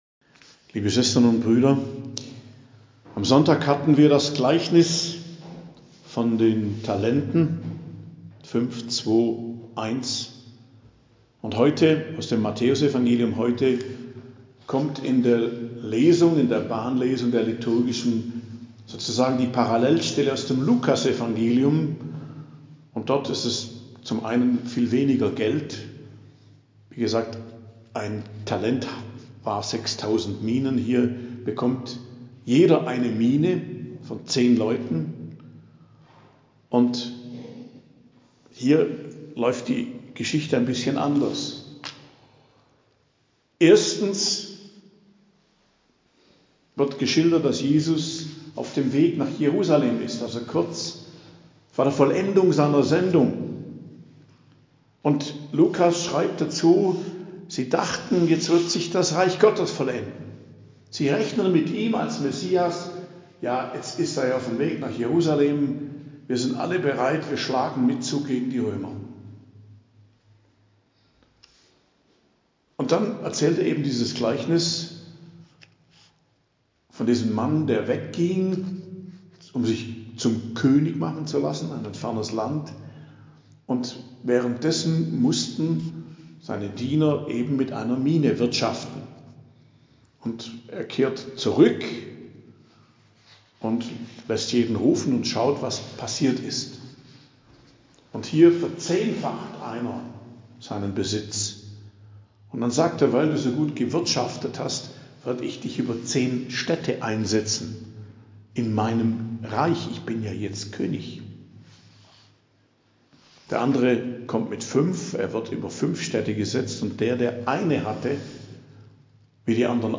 Predigt am Mittwoch der 33. Woche i. J., 22.11.2023